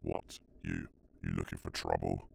Voice Lines / Marcel drug fiend
what what are you looking for trouble.wav